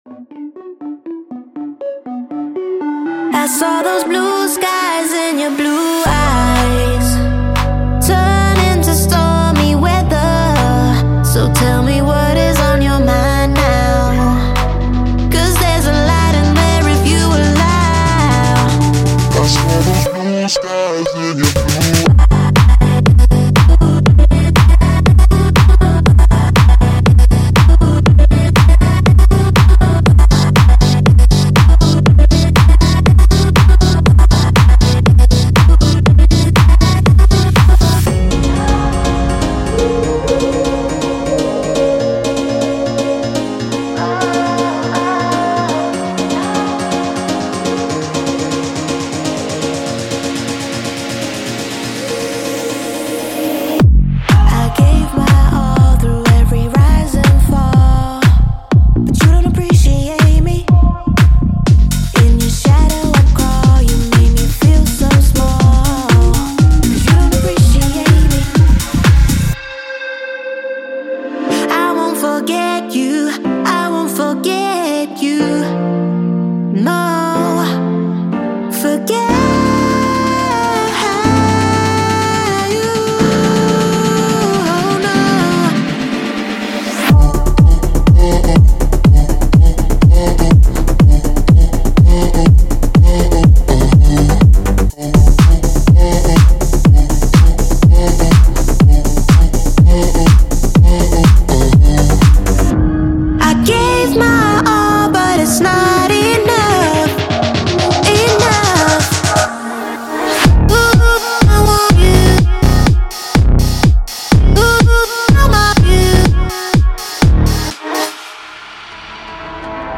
人声钩弦、乐句和即兴演奏都配有干湿版本，用途多样。
20 Vocal Hooks (Wet & Dry)
34 Drum Loops